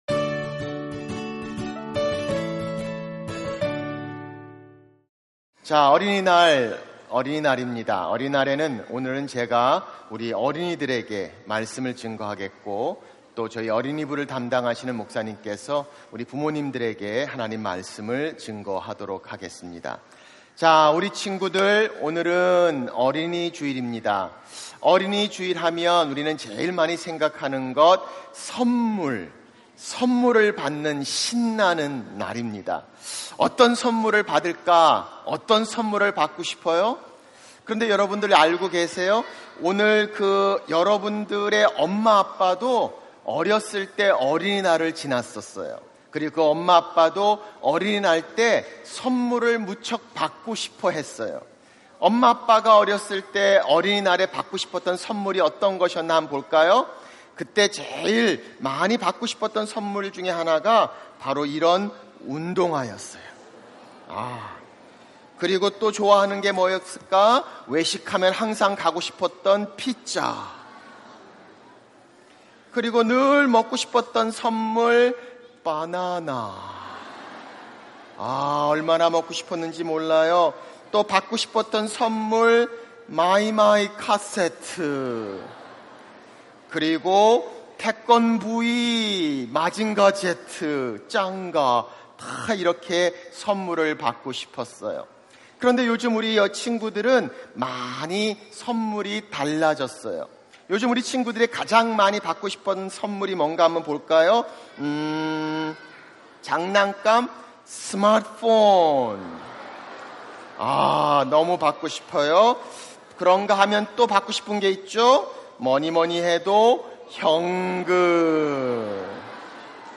설교 | 가장 큰 선물